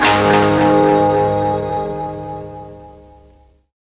Track 07 - Guitar Hit.wav